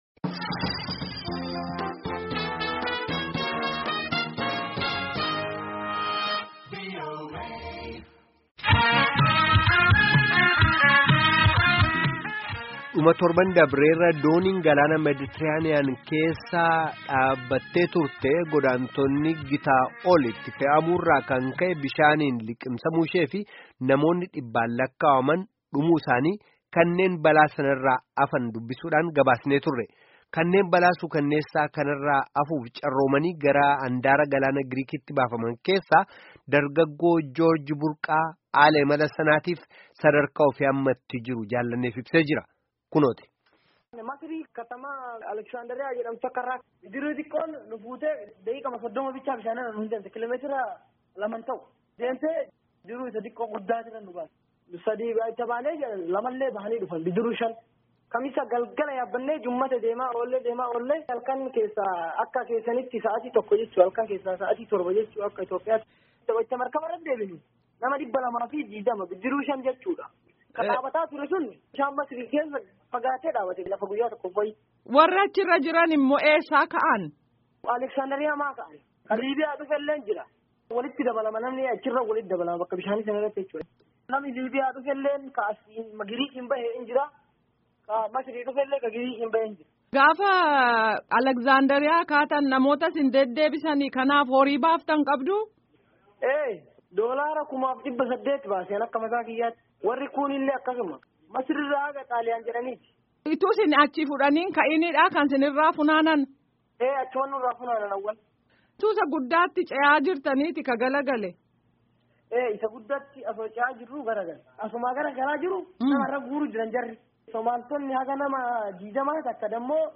Gaaffii fi deebii guutuu